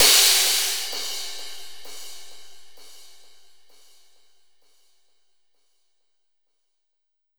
Sf Crash.wav